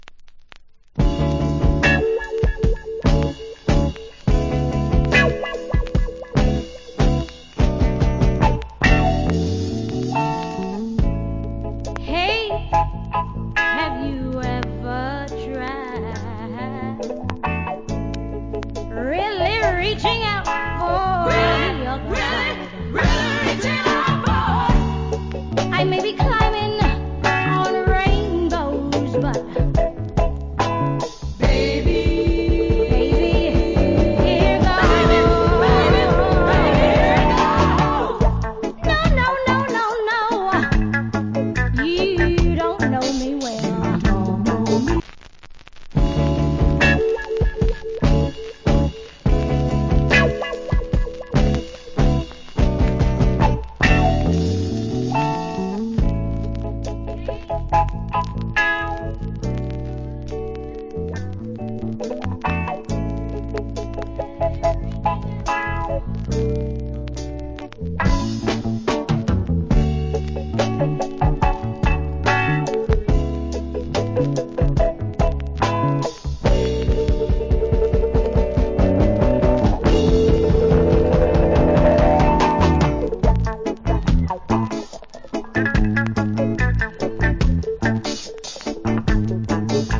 Nice Femake Reggae Vocal.